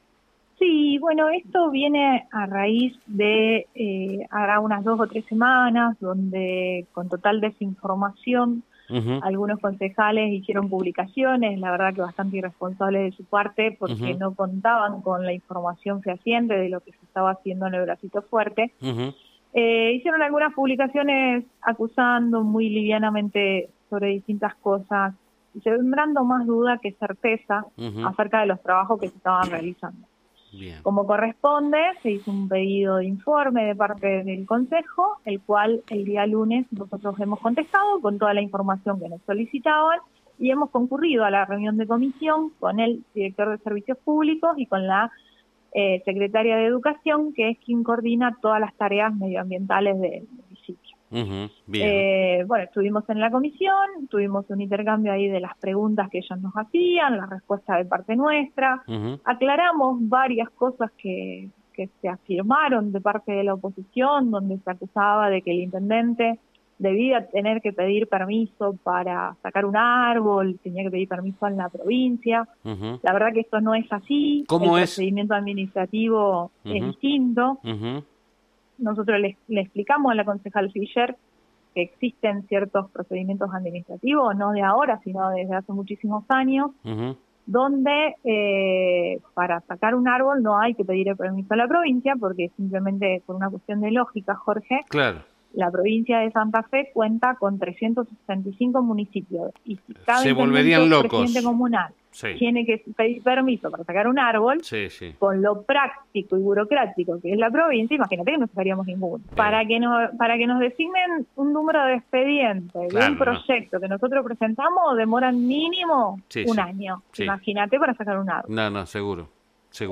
En la mañana de hoy tuvimos la oportunidad de hablar con la secretaria de gobierno de la municipalidad con respecto a su visita al concejo y a la respuesta a la minuta de comunicación sobre la extracción de especies arbóreas en la ciudad.
Daniela Pérez Secretaria de Gobierno de la municipalidad de Armstrong